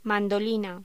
Locución: Mandolina
voz